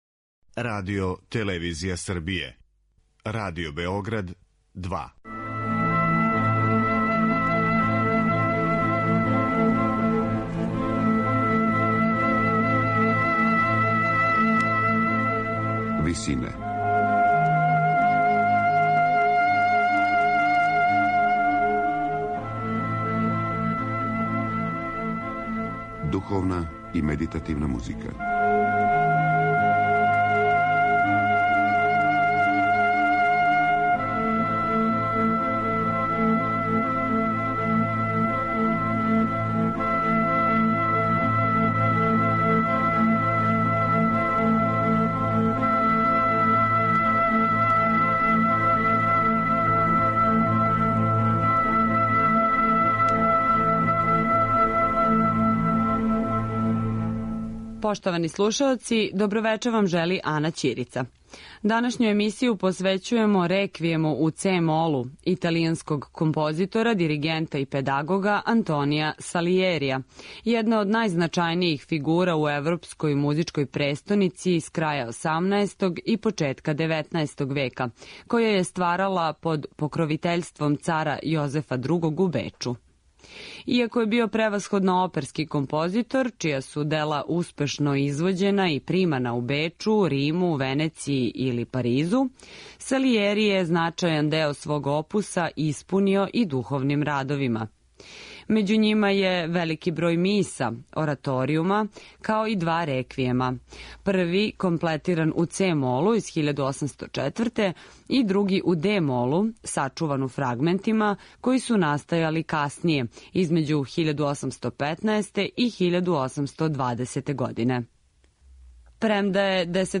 Реквијем у це-молу